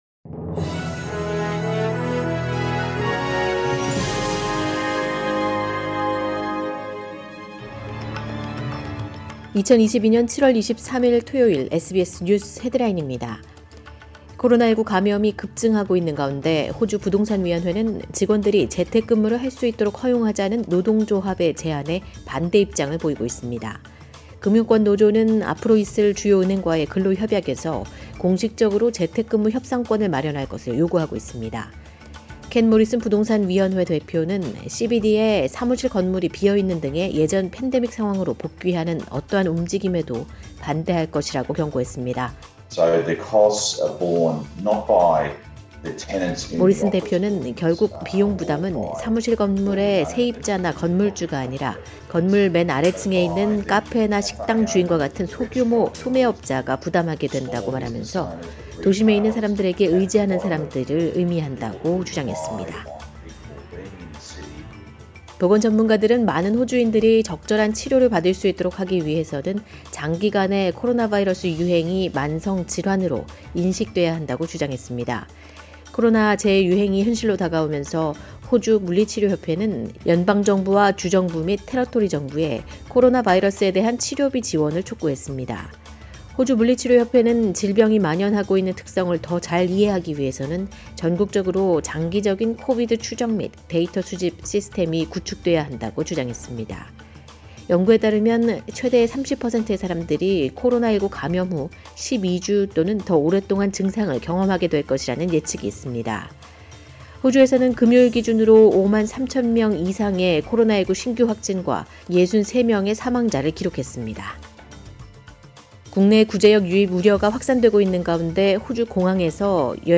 2022년 7월 23일 토요일 SBS 한국어 간추린 주요 뉴스입니다.